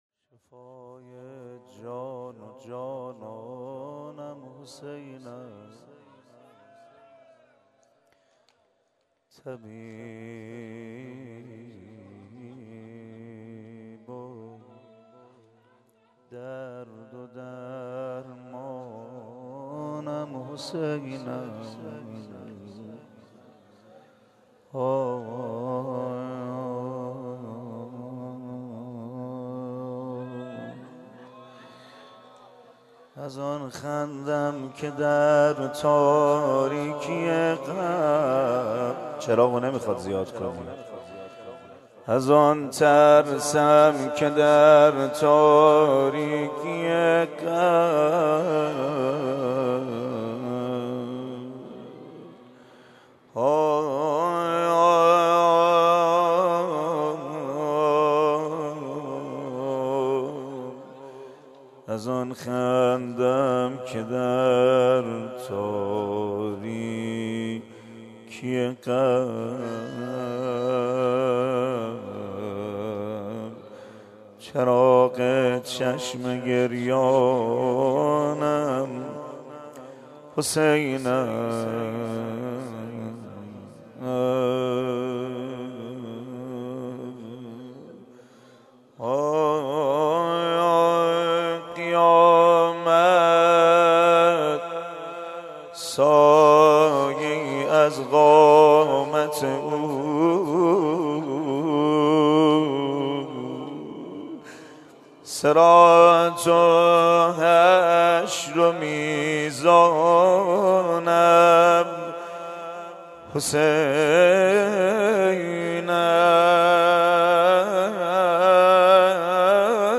روضه دعا ومناجات
سینه زنی